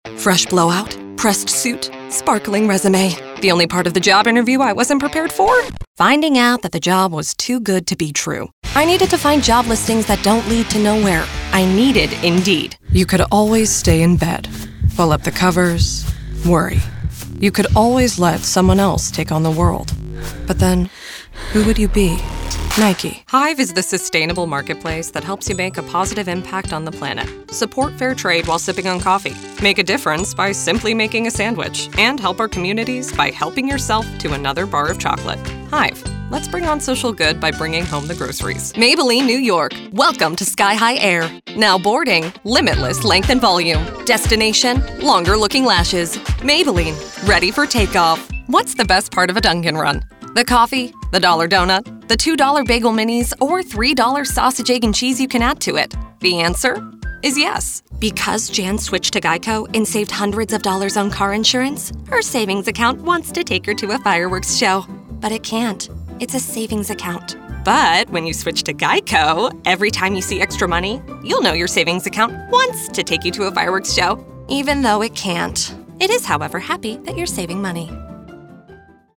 Commercial Demo
Accents and Dialects
RP (certified standard), British Working Class, General US - Western, Southern, Midwestern, Valley